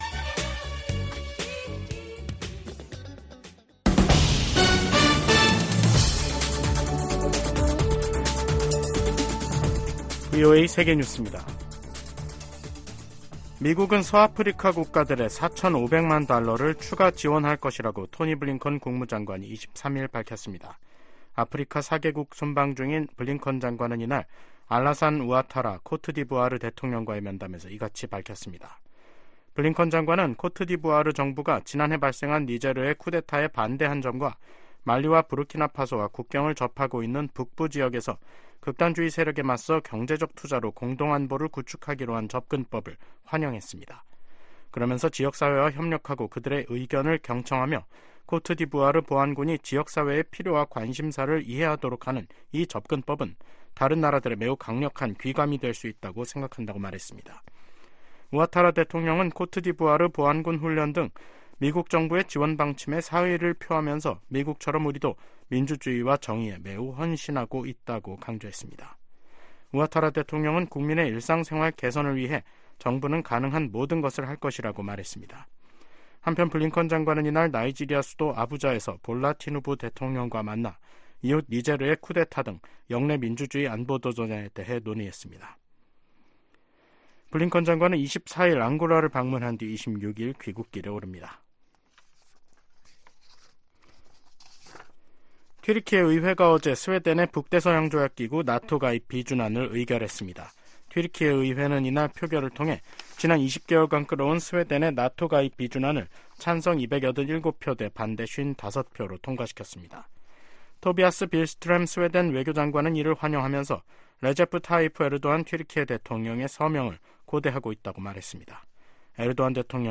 VOA 한국어 간판 뉴스 프로그램 '뉴스 투데이', 2024년 1월 24일 3부 방송입니다. 북한이 서해상으로 순항미사일 여러 발을 발사했습니다.